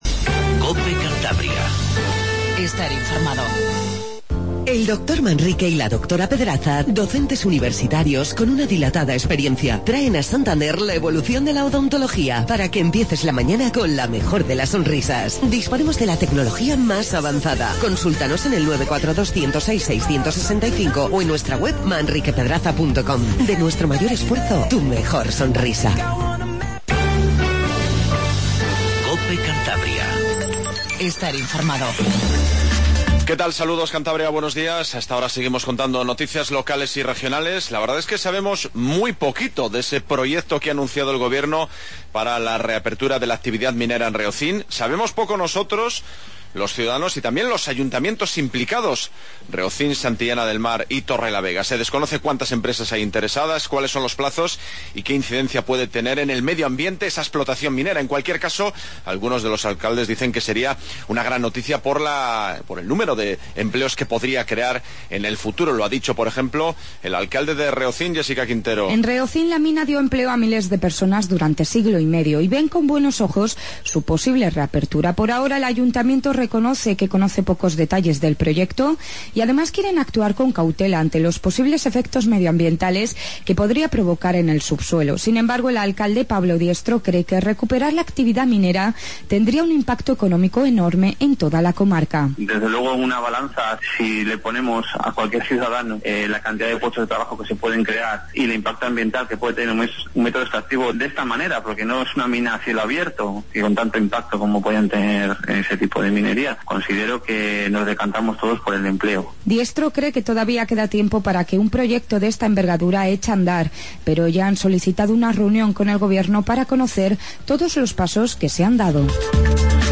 INFORMATIVO MATINAL 08:20